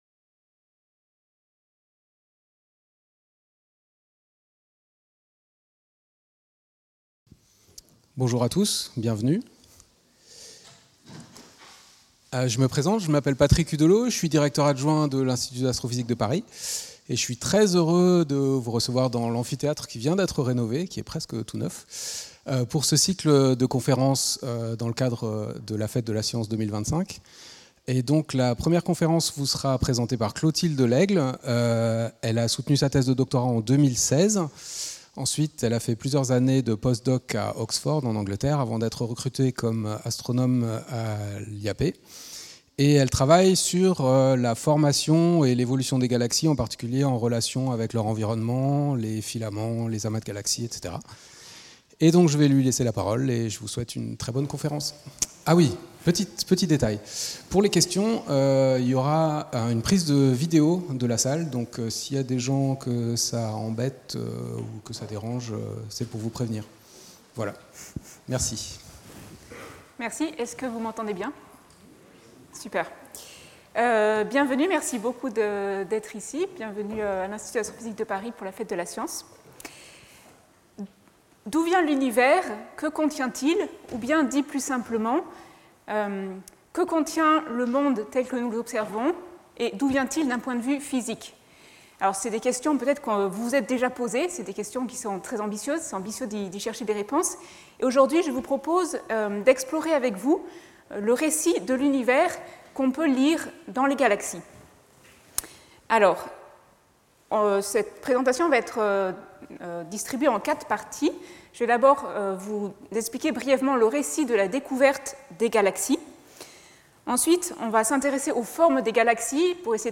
Cette conférence
a été organisée pour la Fête de la science